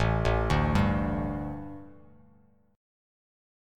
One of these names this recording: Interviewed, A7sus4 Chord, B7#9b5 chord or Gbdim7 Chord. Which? A7sus4 Chord